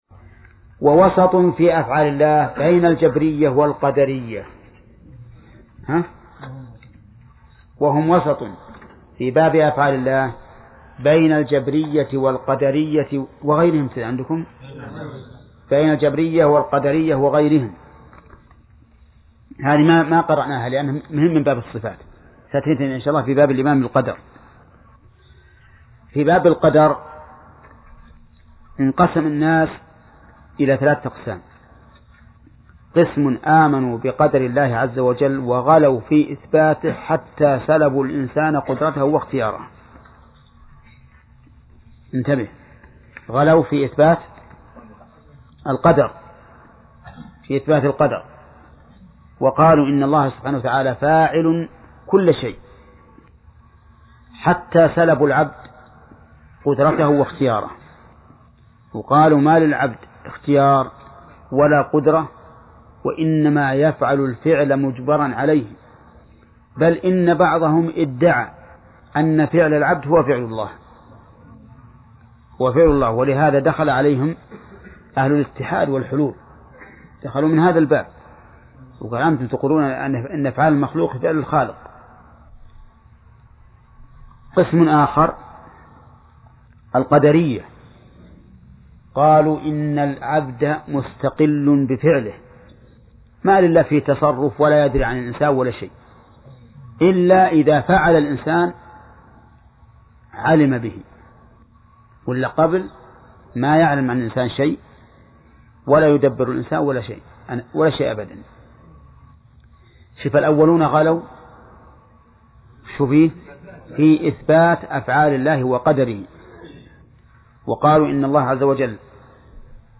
درس (26) : من صفحة: (67)، (قوله: الأصل الثاني ....)، إلى صفحة: (89)، (قوله: فصل في قرب الله تعالى ....).